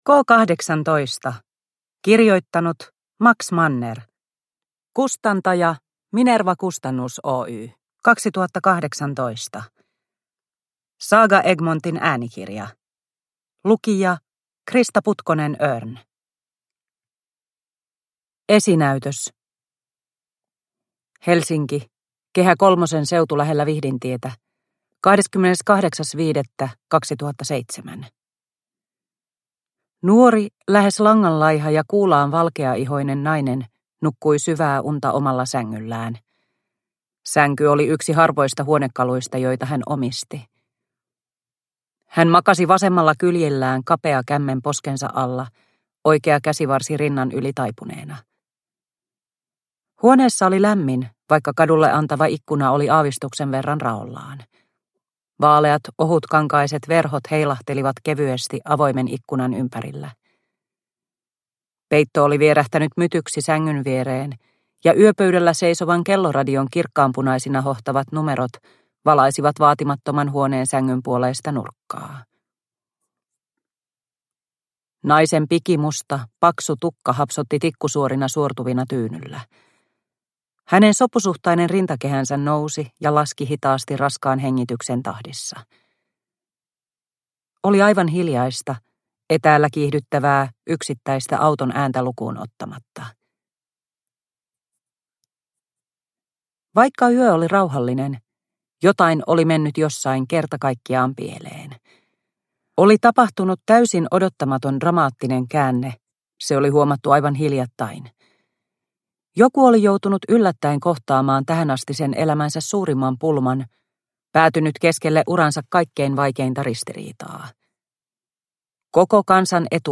K18 / Ljudbok